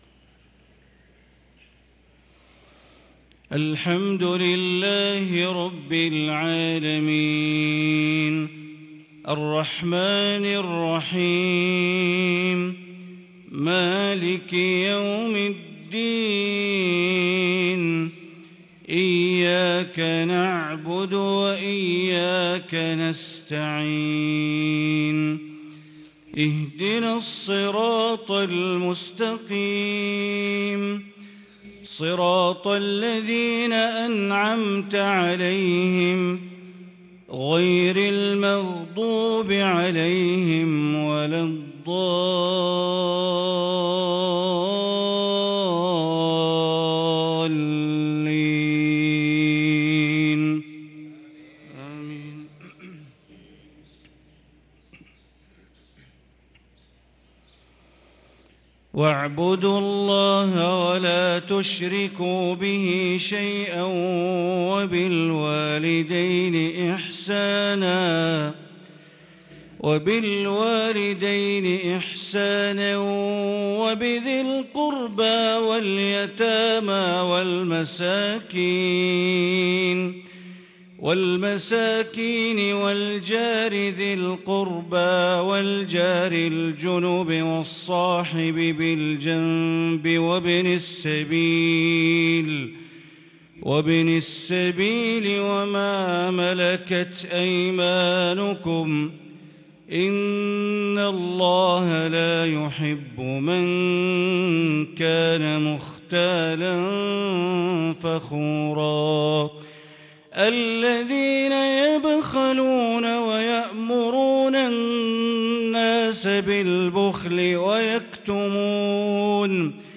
صلاة المغرب للقارئ بندر بليلة 26 ربيع الأول 1444 هـ
تِلَاوَات الْحَرَمَيْن .